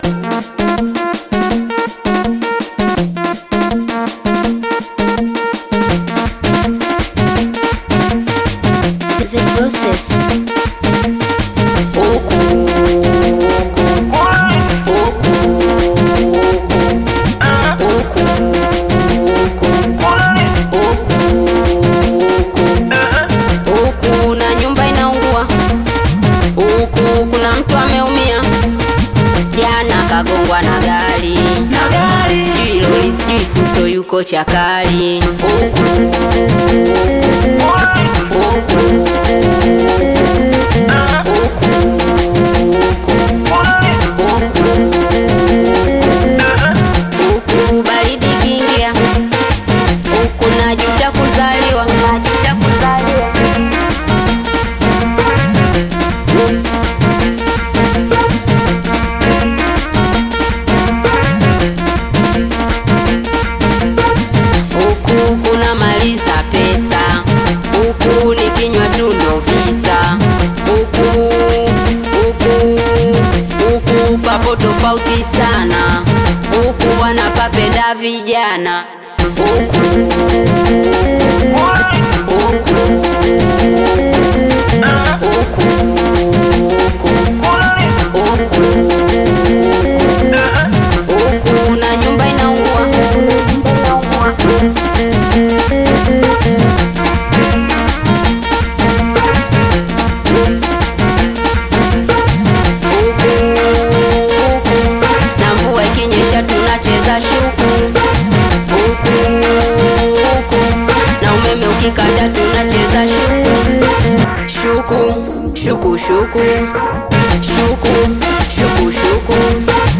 Tanzanian Bongo Flava/Afro-Pop single
smooth vocal delivery and modern Tanzanian sound
Singeli